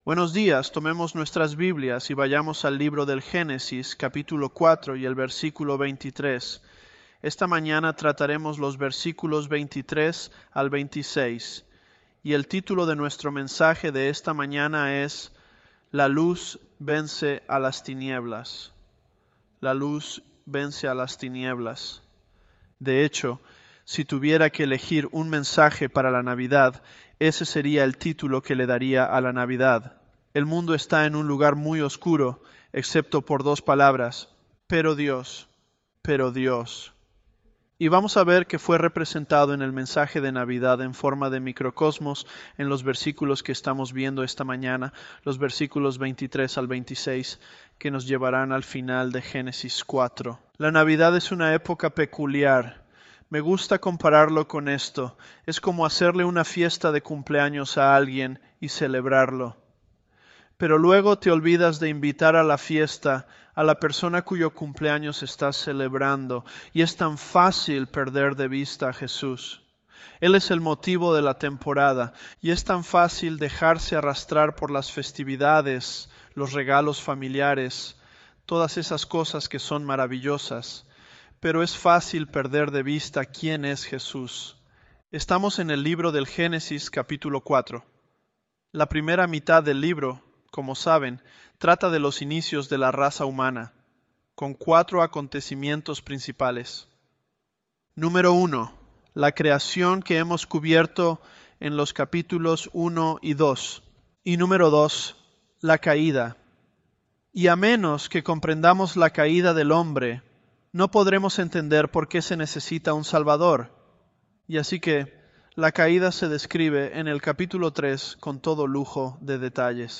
Home / Sermons / Genesis 019 - La Luz Vence a lasTinieblas.
ElevenLabs_Genesis-Spanish019.mp3